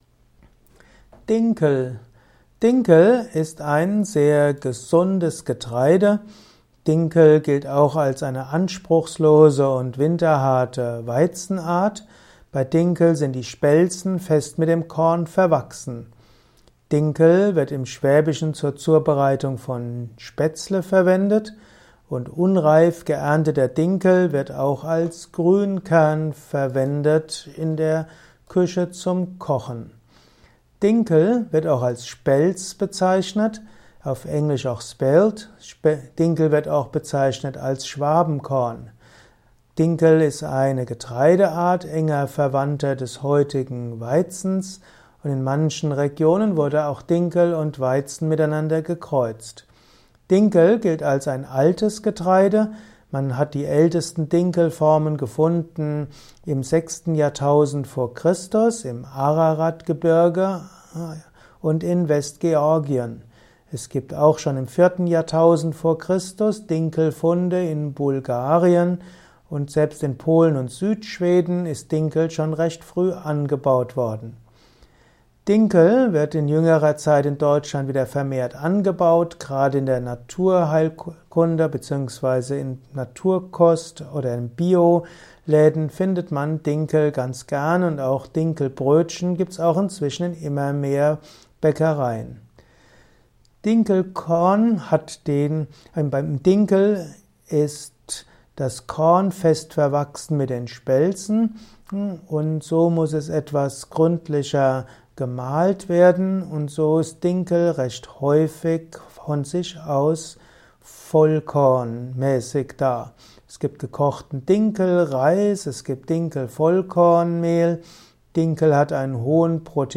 Ein Kurzvortrag über die Getreideart Dinkel